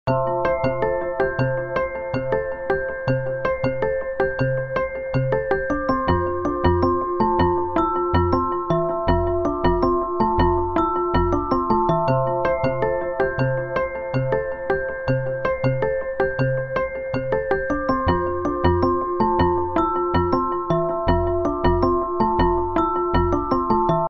a perfect blend of modern beats and sleek sounds.